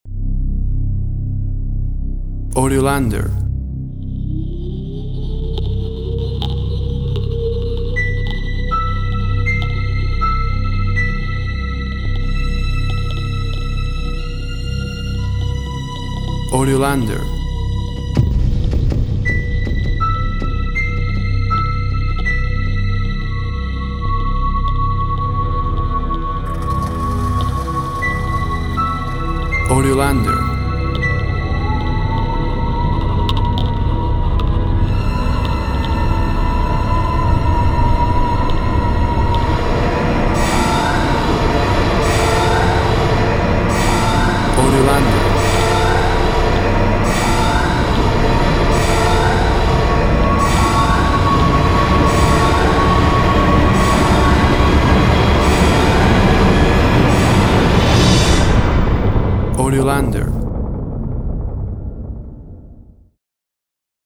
Horror music textures, with suspenseful approaches.
Tempo (BPM) 80